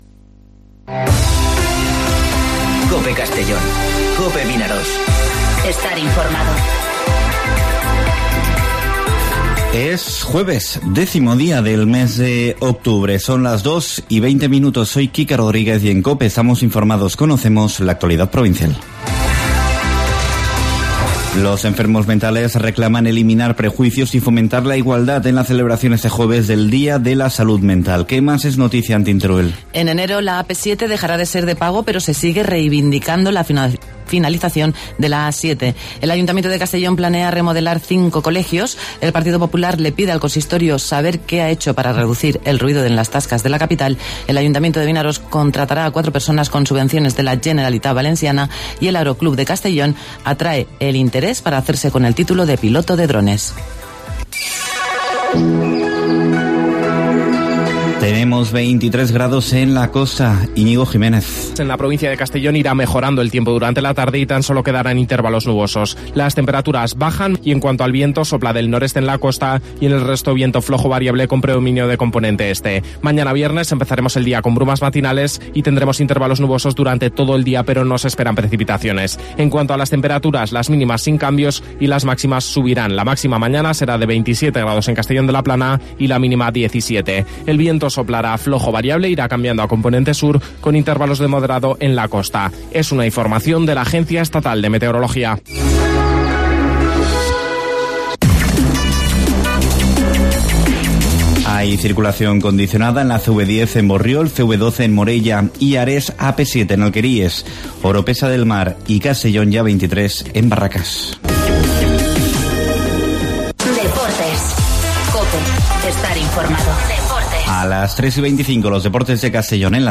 Informativo Mediodía COPE en Castellón (10/10/2019)